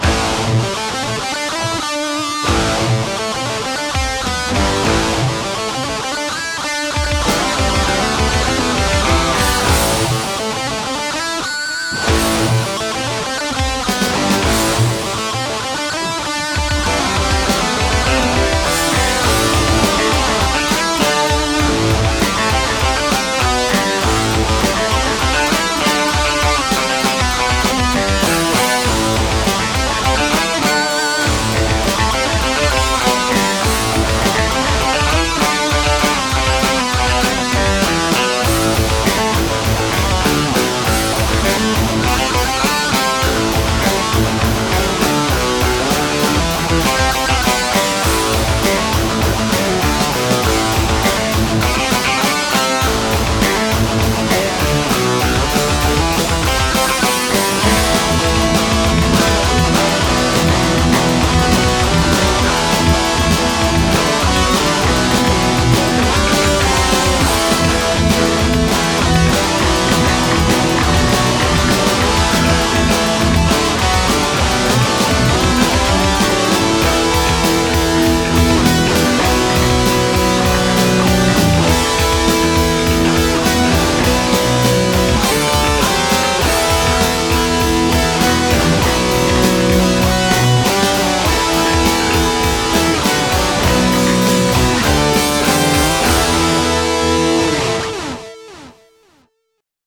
Once again, a little riff written on the nylon string, then fleshed out in the basement over a few hours. I've found a good rhythm of recording, but the middle section feels a little messy. Still, it's cool and the harmonies were fun to play.